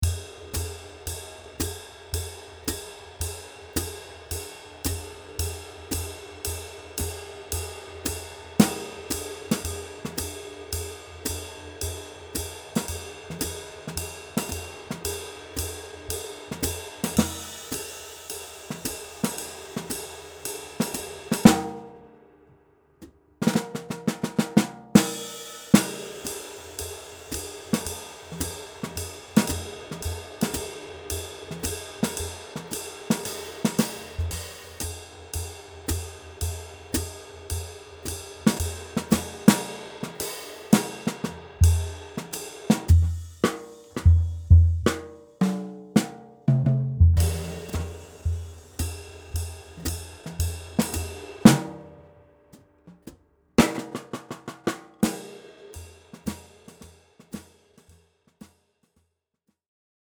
爵士架子鼓采样包
Brush Kit WAV Project 分轨文件